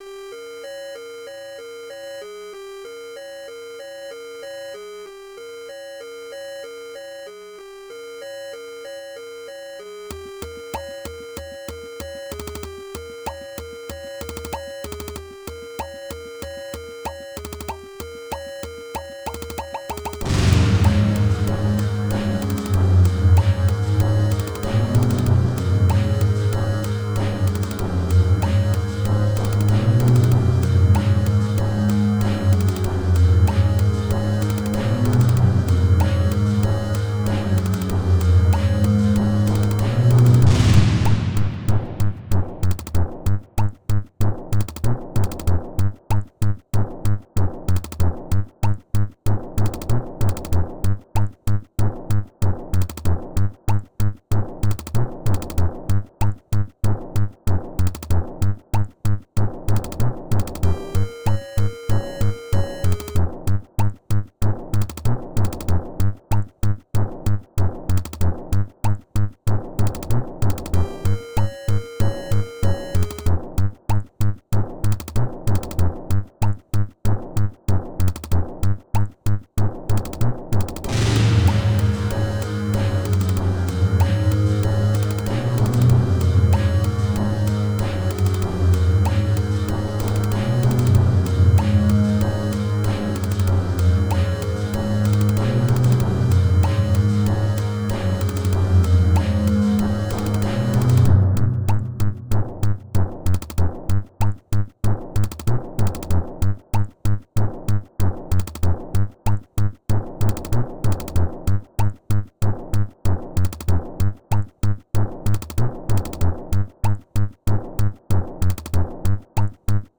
my friend had me make beats for an album he never attempted